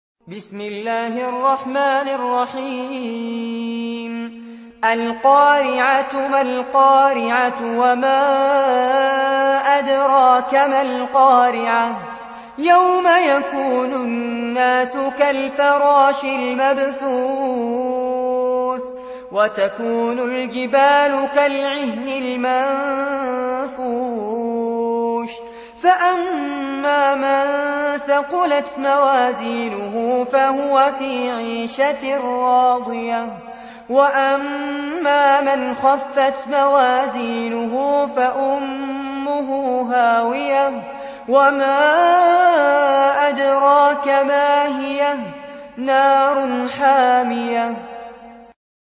Récitation par Fares Abbad